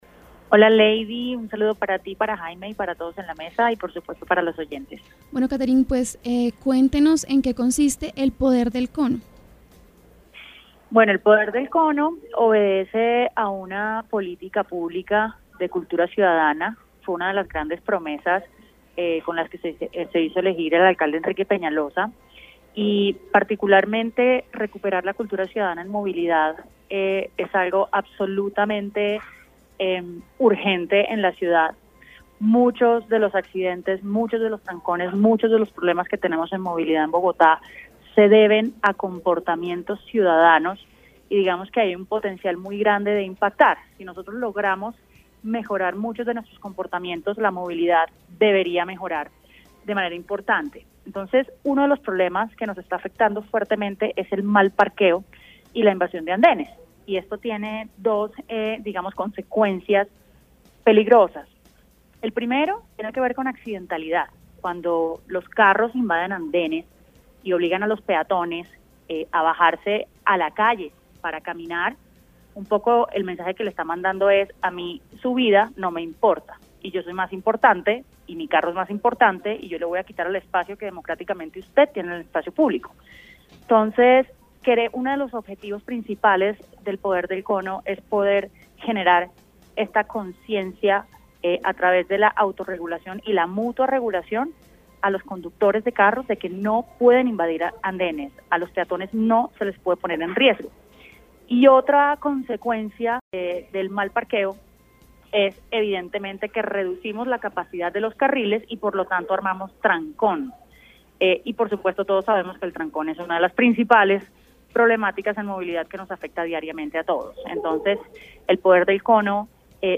En diálogo con Uniminuto Radio estuvo Catherine Juvinao, jefe de cultura ciudadana de la Secretaría de Movilidad, quien habló sobre la campaña “El Poder del Cono”, que comienza a recorrer las calles de Bogotá sancionando , de una manera muy particular, a los mal parqueados.